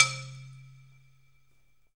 Index of /90_sSampleCDs/USB Soundscan vol.02 - Underground Hip Hop [AKAI] 1CD/Partition D/06-MISC
BALAFON 1 -R.wav